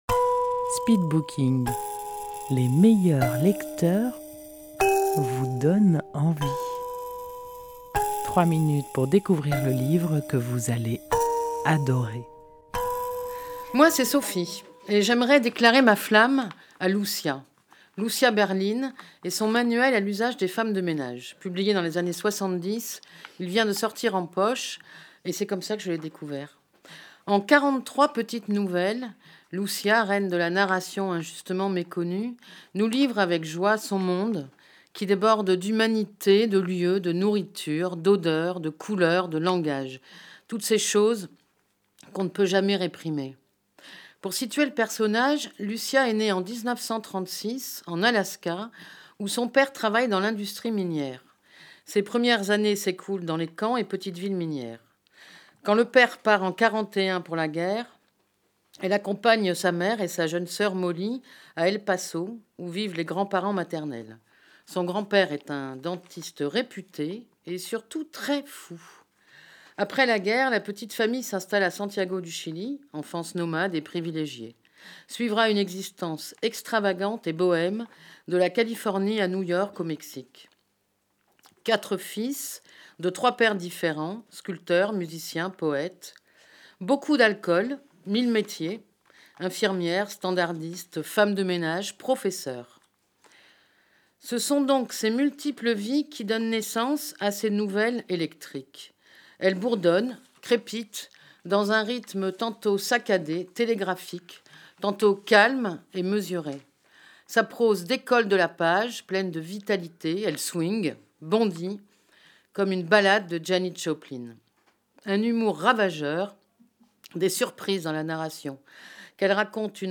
Rendez-vous au speed booking : les meilleurs lecteurs vous font partager leur passion pour un livre en 3 minutes chrono. Enregistré en public au salon de thé Si le cœur vous en dit à Dieulefit.